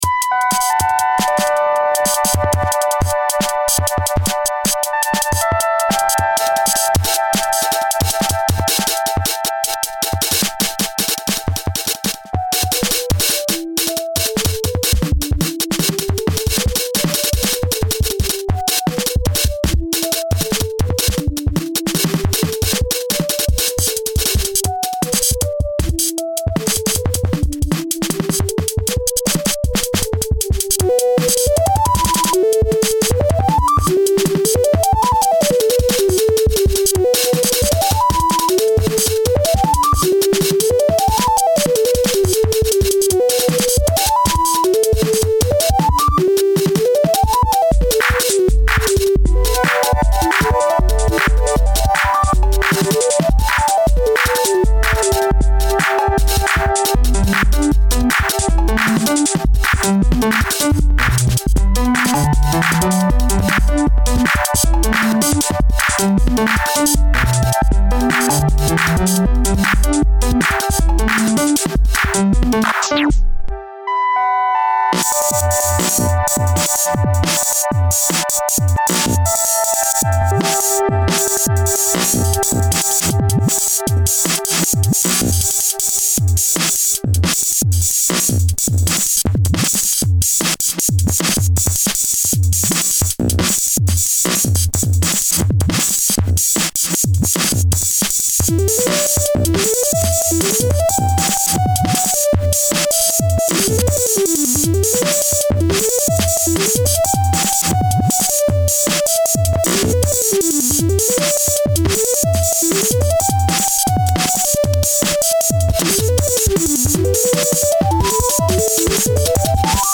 Filed under: Uncategorized Instrumental | Comments (2)
I really like the synths. Right before the end of the song when the drums mellow out and then stop is really great, maybe you could expand on that. The drums are awesome but I cant help but feel they might be detracting from the buttery melodies.
I like when the melody gets all twittery towards the middle and I love that the drums are super complex.